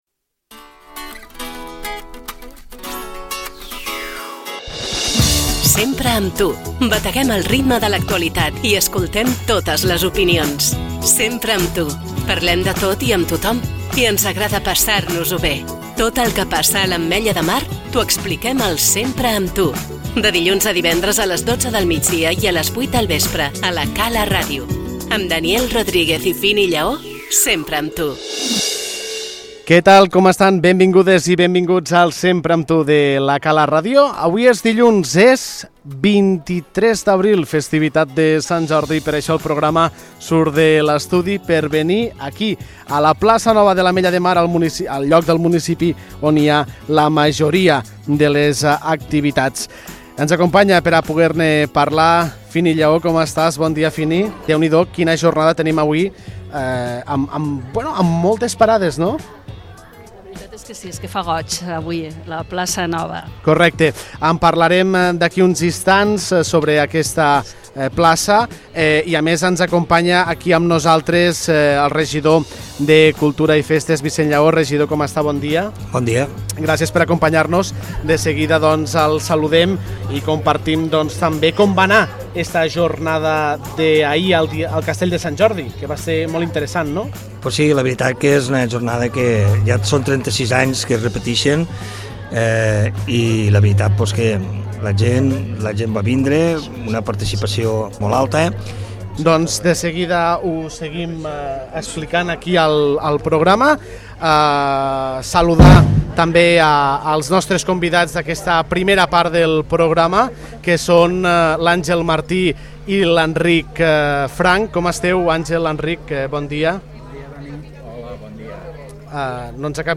El ‘Sempre amb tu’ ha sortit al carrer aquest Sant Jordi per viure en directe com se celebra el dia del llibre i la rosa, amb entrevistes a escriptors locals i un ‘Jo parlo’ calero molt especial.